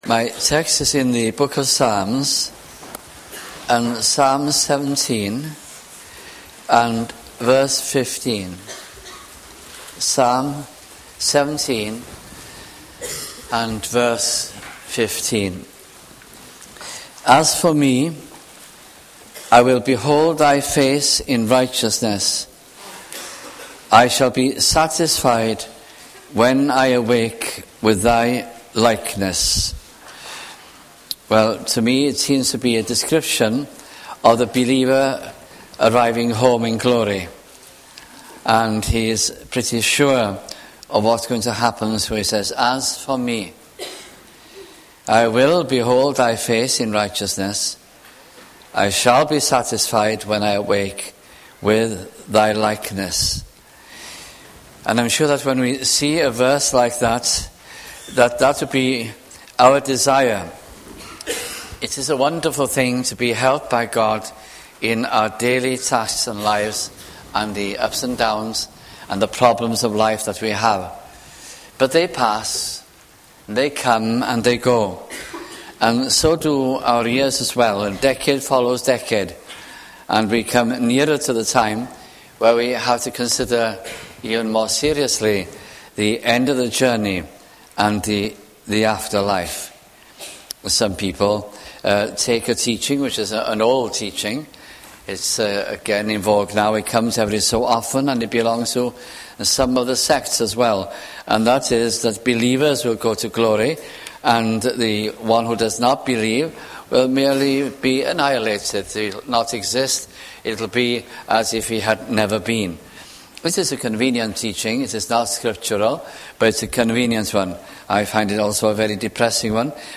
» Psalms Gospel Sermons